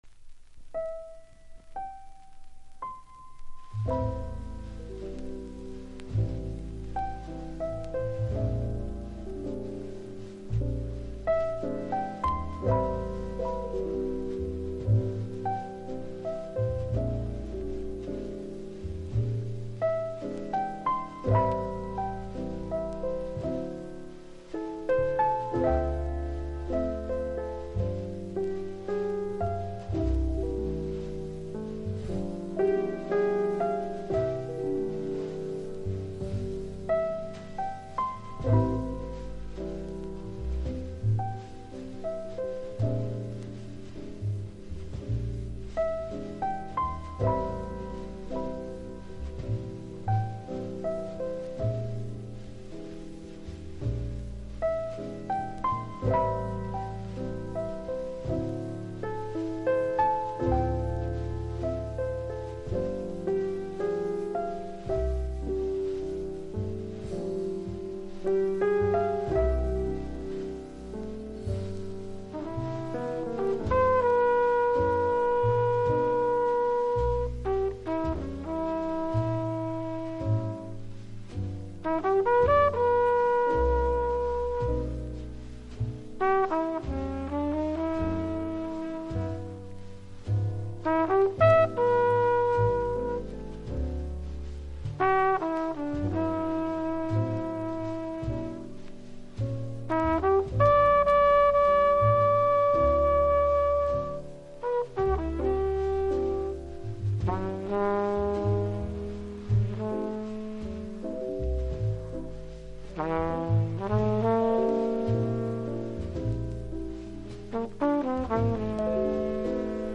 （盤反り軽くありますが音に影響なし）（プレス・小傷によりチリ、プチ…